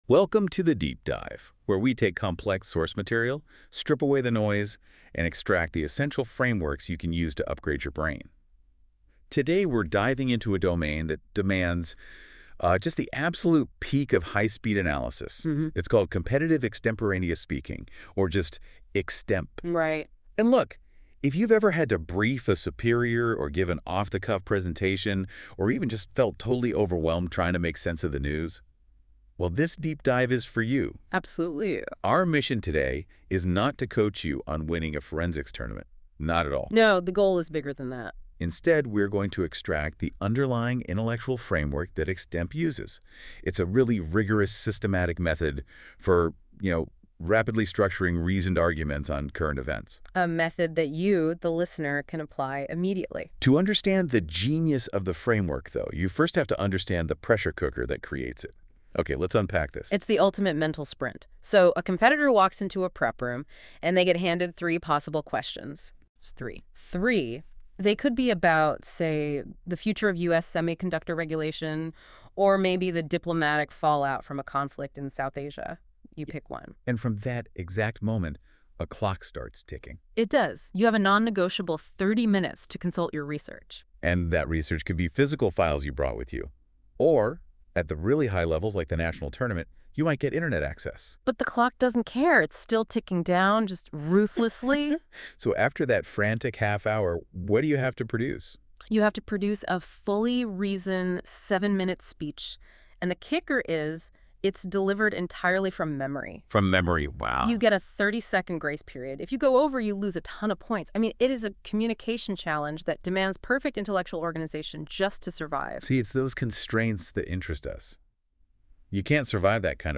This 15-minute audio file provides a discussion of key Extemporaneous Speaking skills in a podcast format. The file was generated by NotebookLM using content developed exclusively by xTempore.